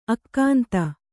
♪ akkānta